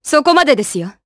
Glenwys-Vox_Skill2_jp.wav